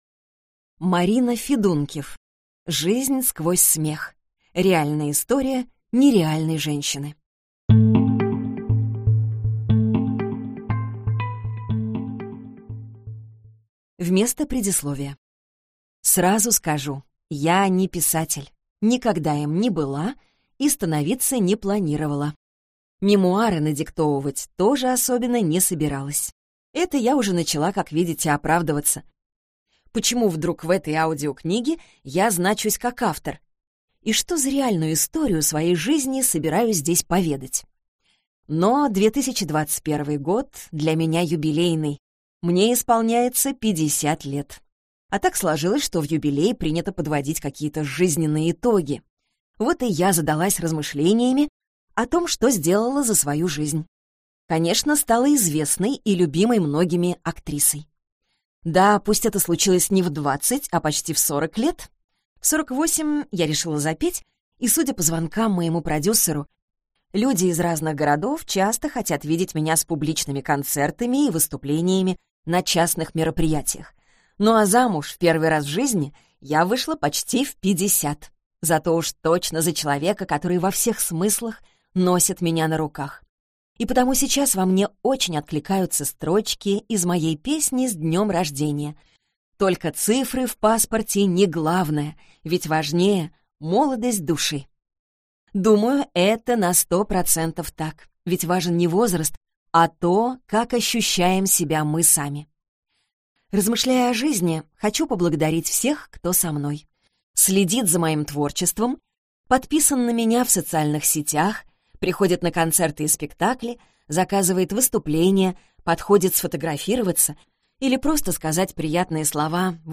Аудиокнига Жизнь сквозь смех. Реальная история нереальной женщины | Библиотека аудиокниг